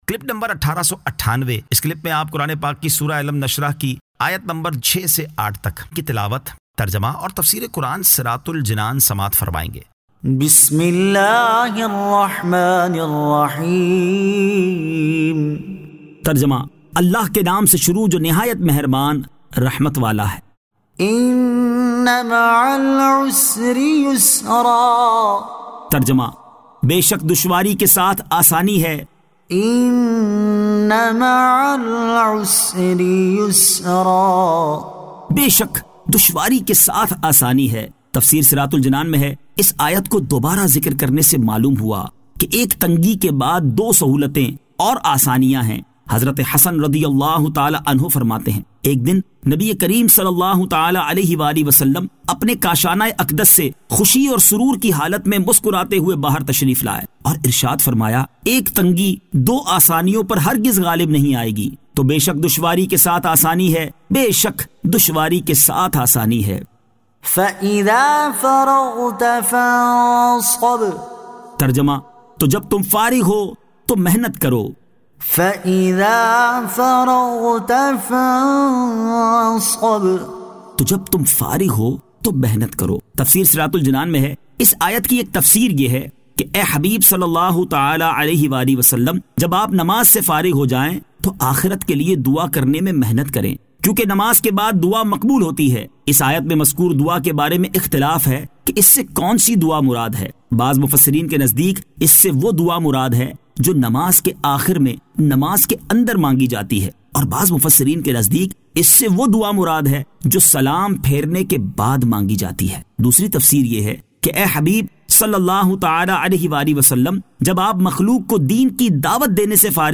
Surah Alam Nashrah 06 To 07 Tilawat , Tarjama , Tafseer